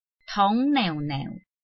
拼音查詢：【南四縣腔】neu ~請點選不同聲調拼音聽聽看!(例字漢字部分屬參考性質)